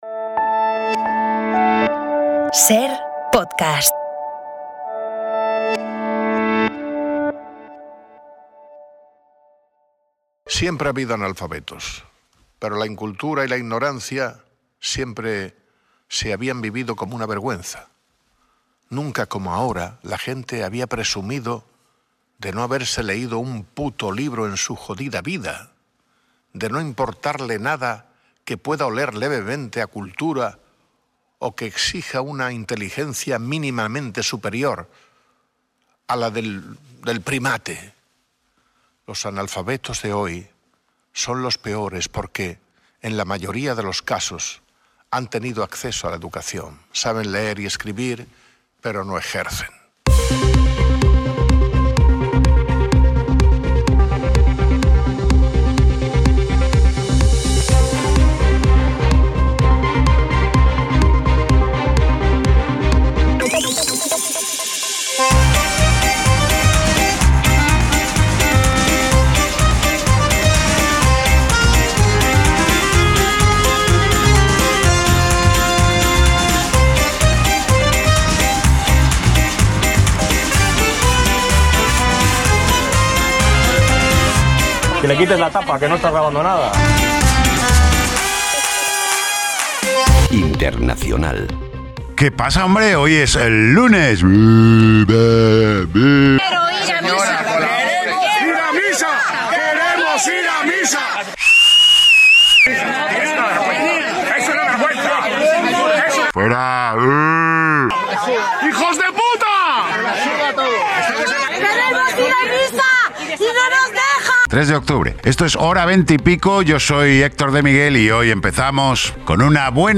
Audio de Humor en la Cadena SER en Podium Podcast